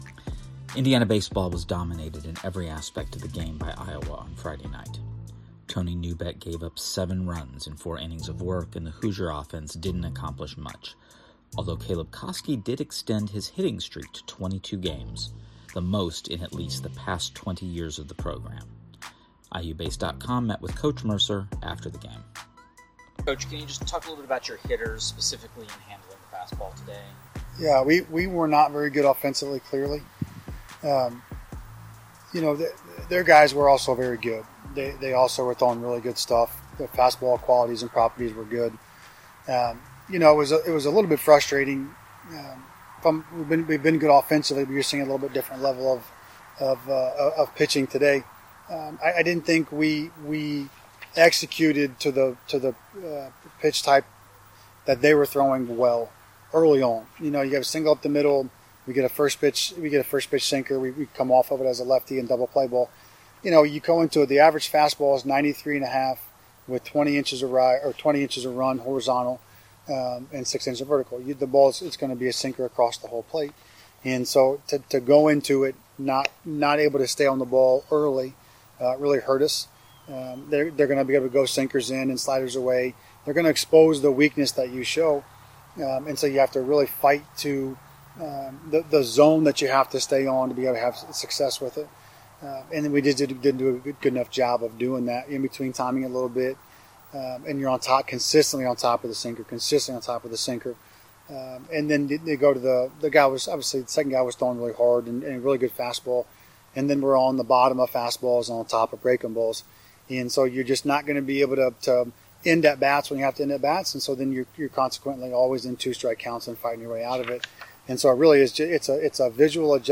Postgame Media – Iowa Friday